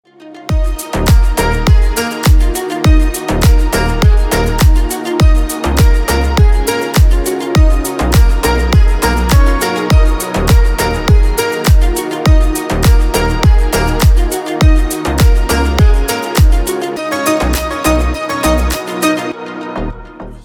Лучший Дип Хаус в рингтонах